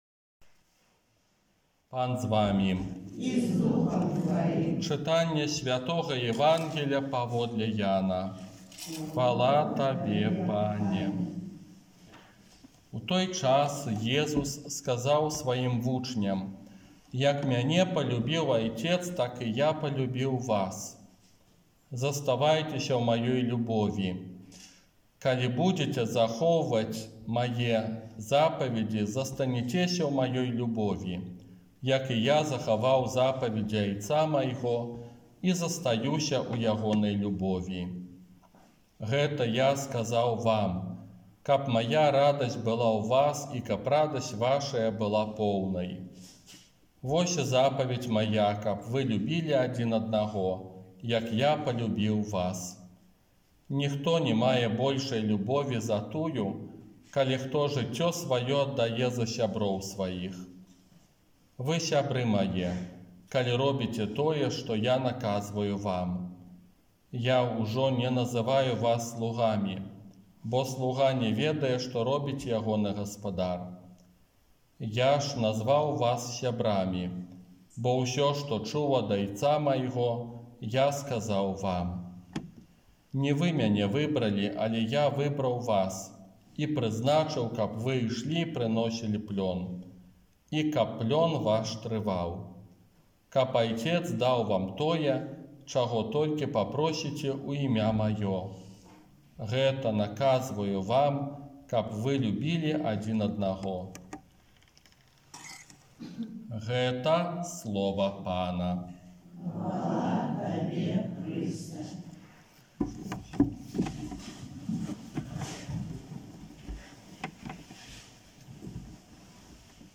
ОРША - ПАРАФІЯ СВЯТОГА ЯЗЭПА
Казанне на шостую велікодную нядзелю